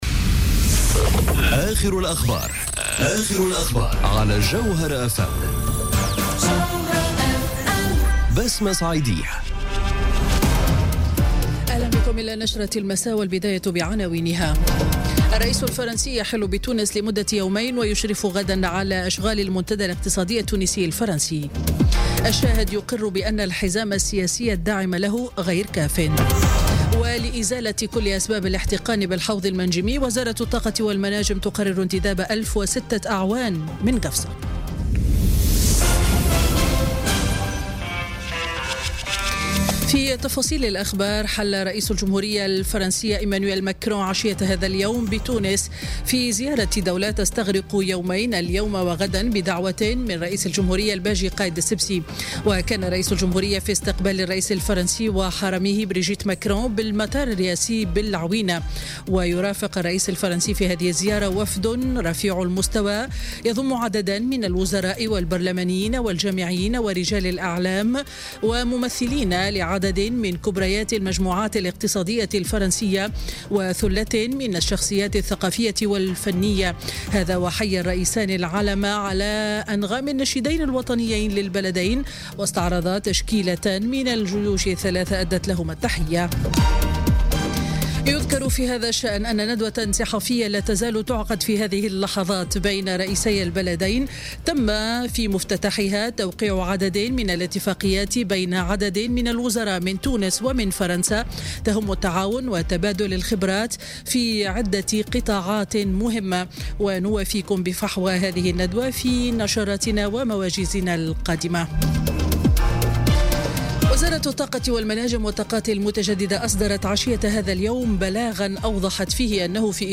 نشرة أخبار السابعة مساءً ليوم الأربعاء 31 جانفي 2018